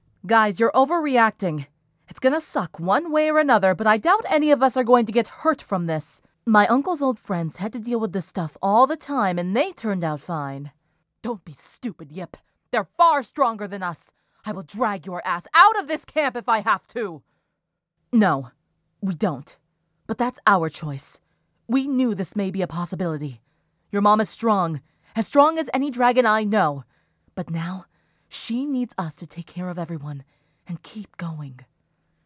Voice Actor
Age ranges between teen and young adult.
BrazenAudition.mp3